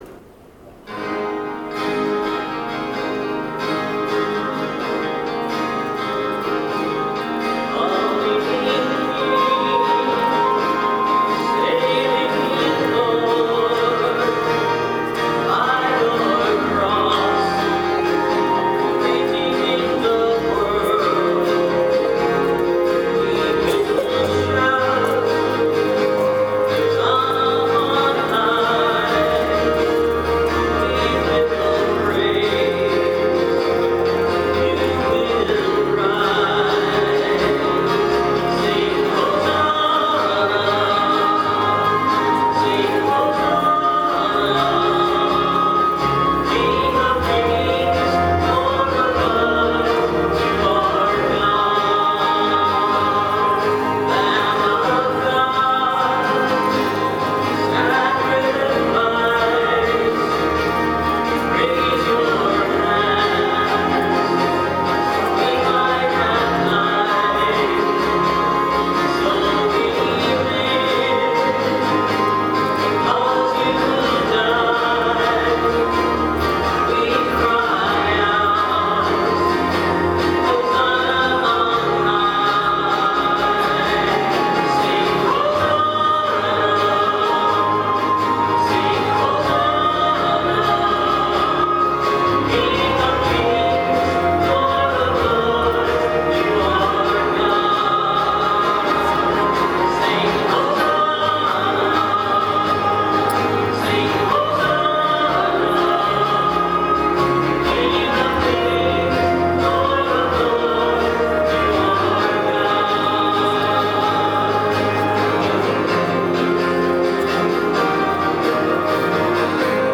Music from the 10:30 Mass on the 1st Sunday in Lent, March 24, 2013:
Perhaps others were not singing as loudly?)